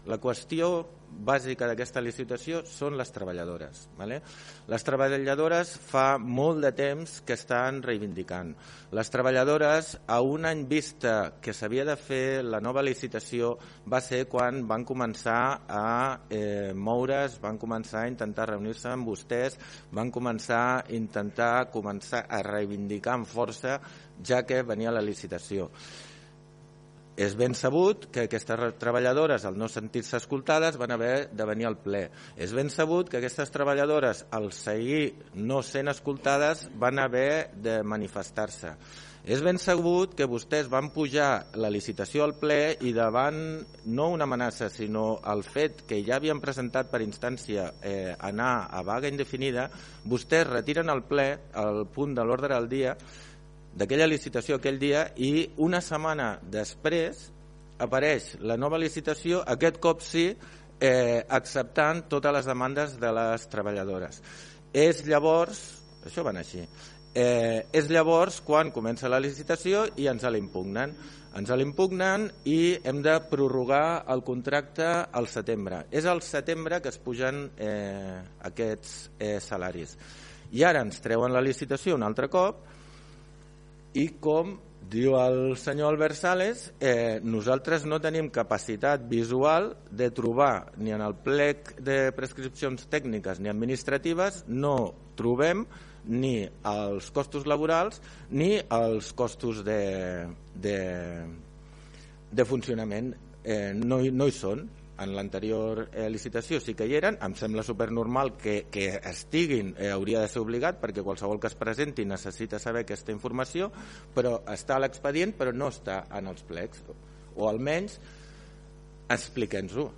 Tant el regidor no adscrit, Albert Sales, com el portaveu d’ERC, Alex Van Boven, van criticar que els costos laborals no estiguessin inclosos en els plecs administratius, tot i aparèixer en l’expedient: